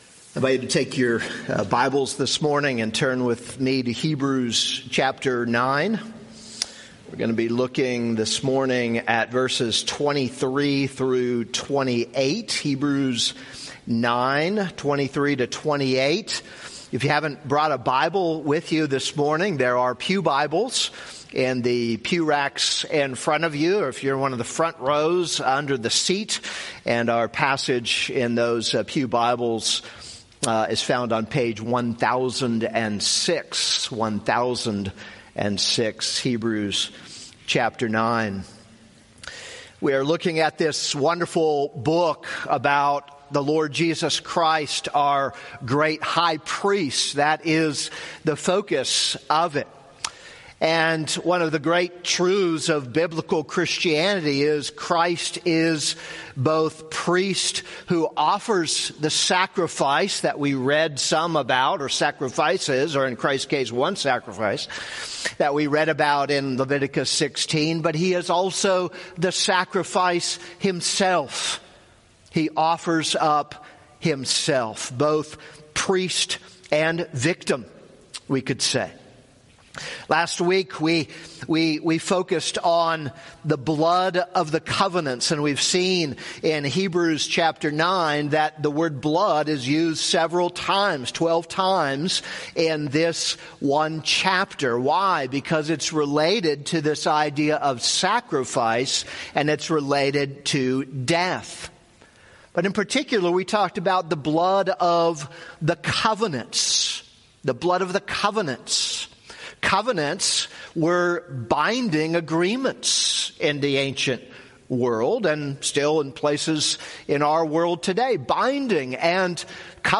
This is a sermon on Hebrews 9:23-28.